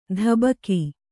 ♪ dhabaki